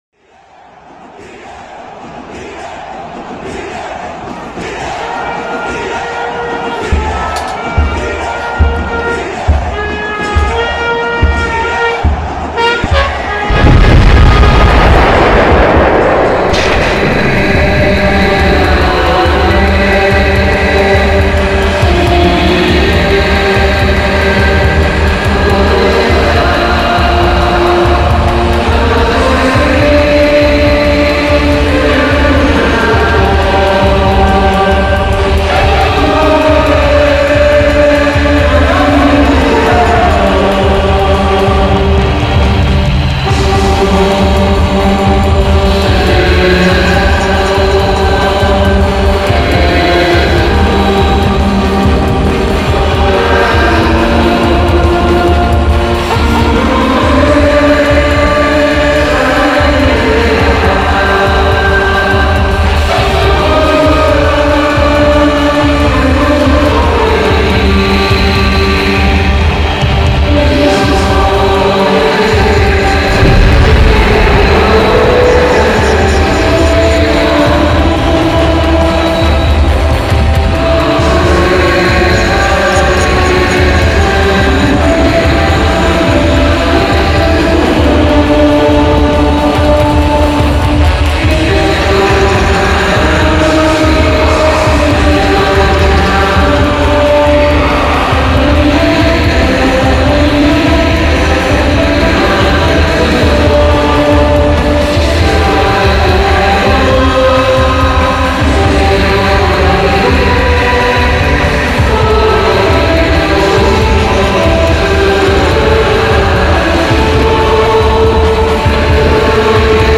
en el estadio